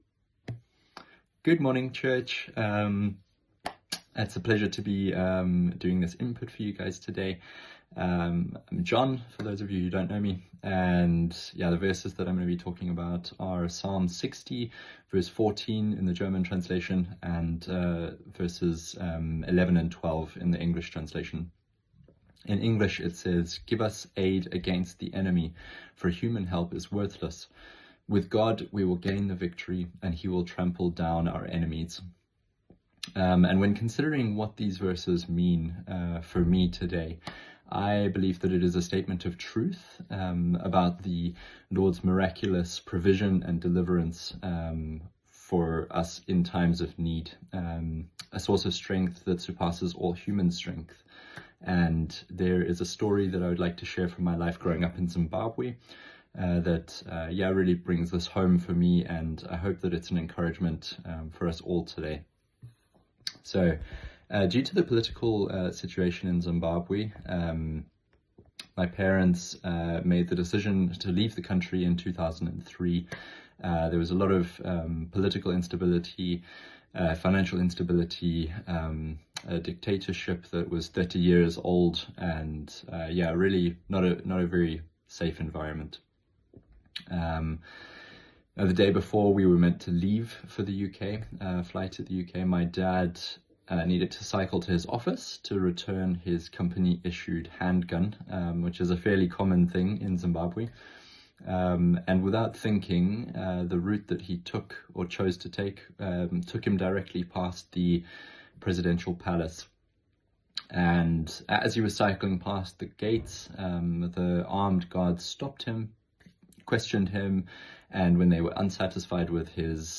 Andacht zu unseren 21 Tagen des Gebets